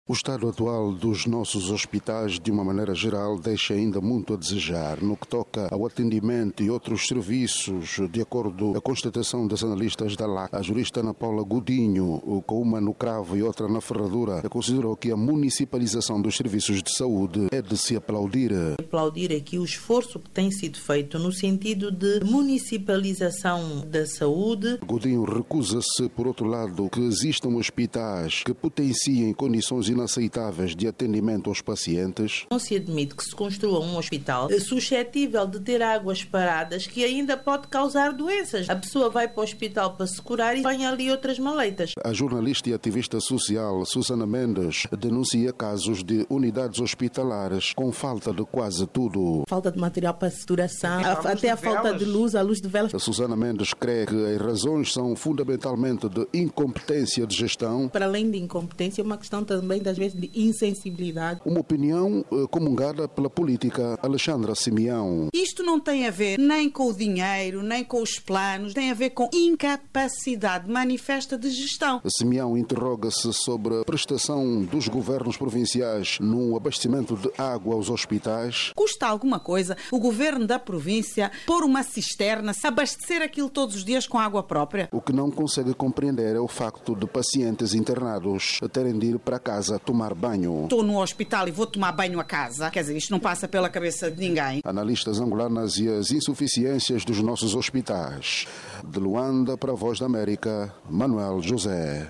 Em debate radiofónico analistas criticam estado dos hospitais